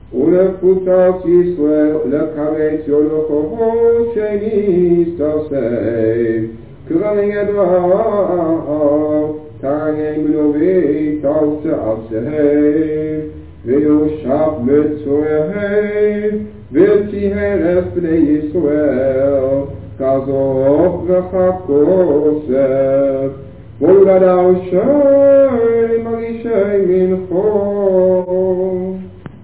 op cassettebandjes